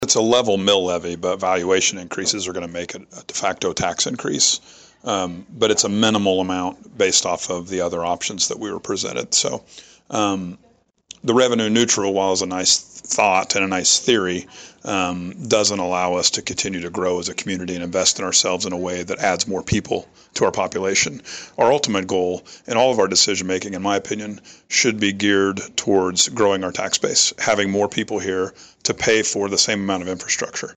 Commissioner Jamie Sauder says he favored approving option two, however, he did have some concerns.